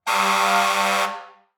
transport
Fire Truck Honk Air Horn